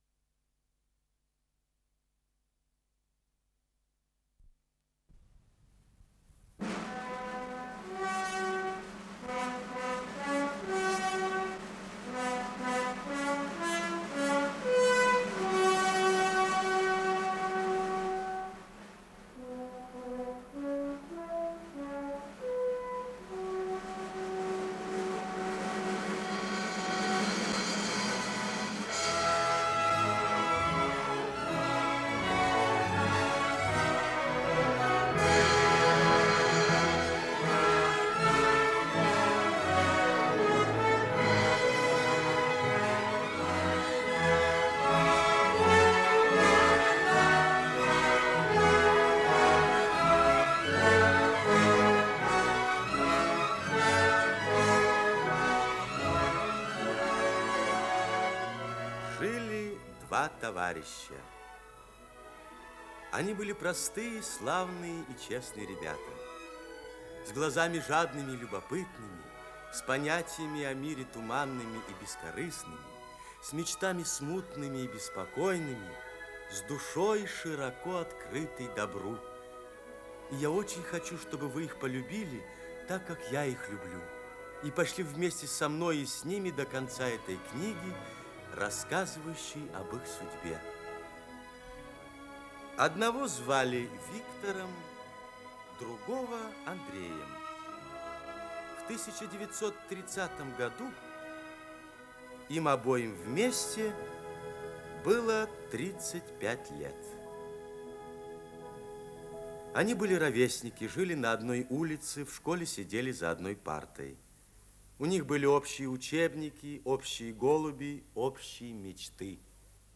Исполнитель: Евгений Семенович Матвеев
Радиокомпозиция